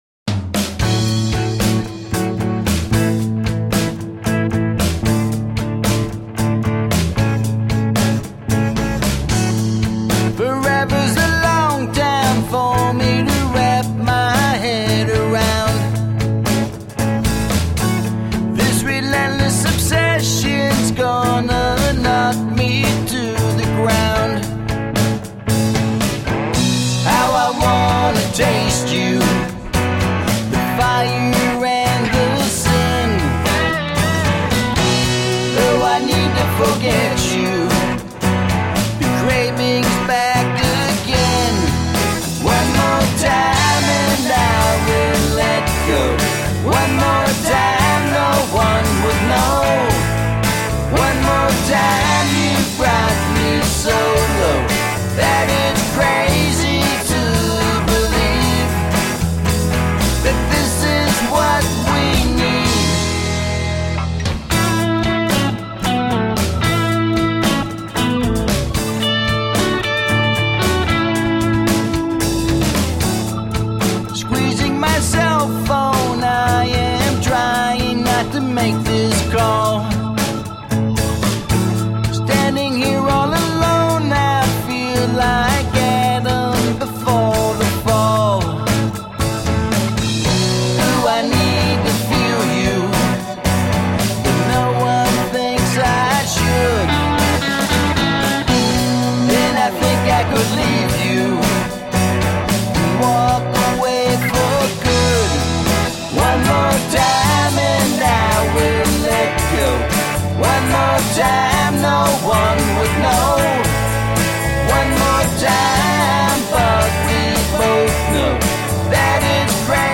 Roots rock meets contemporary folk.
The result is a more direct "guitar rock" presentation.
Tagged as: Alt Rock, Folk